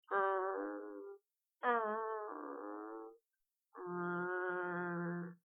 Rufe sind leise und fast sanft zu nennen.